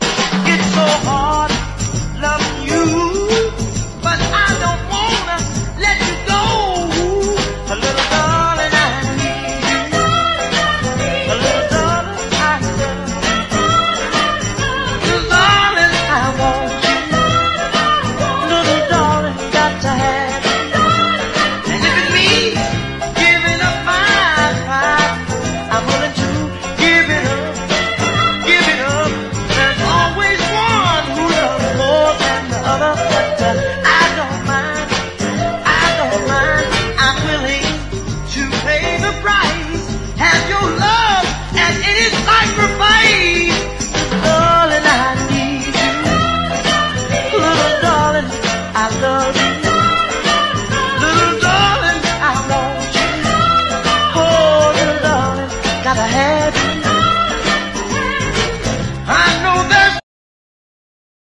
トロピカルなギター・カッティングが意表を突く